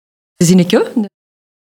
uitspraak Zinneke
zinneke_prononciation.mp3